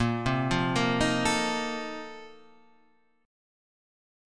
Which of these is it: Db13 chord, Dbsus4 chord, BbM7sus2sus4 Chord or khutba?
BbM7sus2sus4 Chord